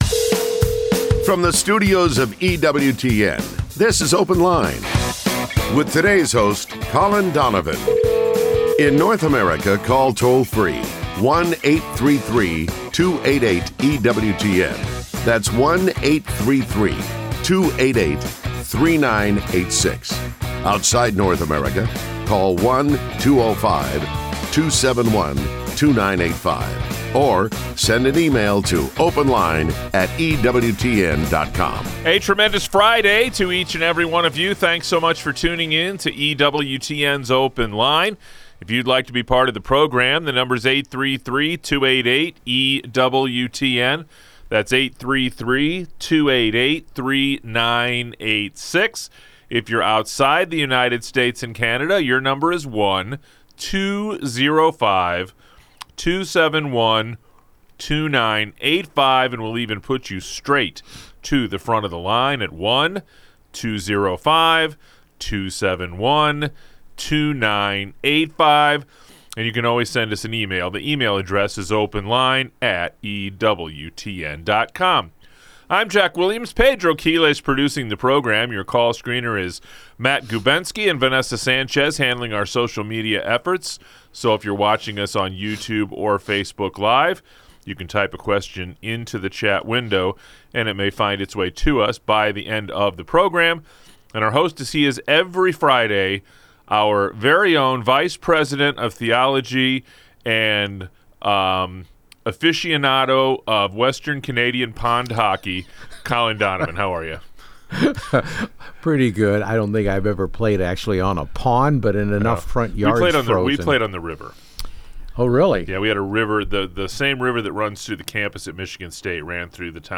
Open Line is a fast-paced call-in show, with a new host every day!